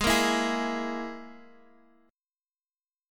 Absus2#5 chord